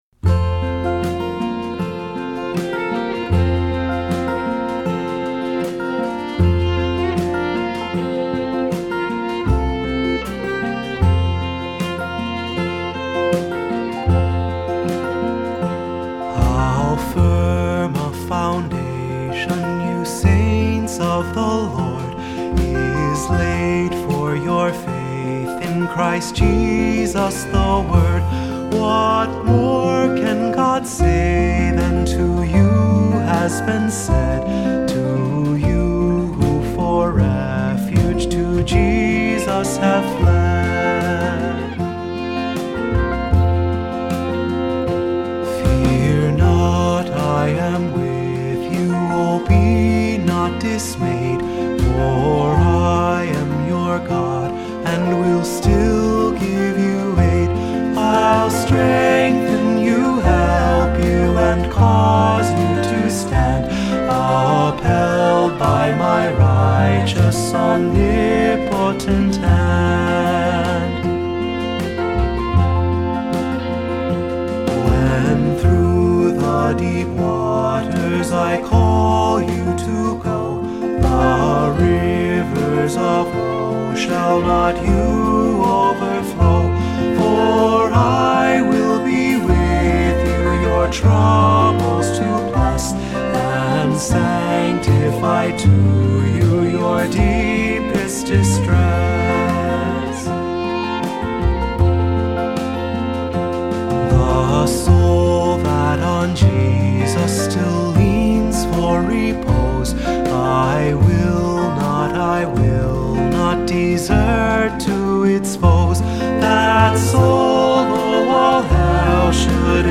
Accompaniment:      Piano
Music Category:      Christian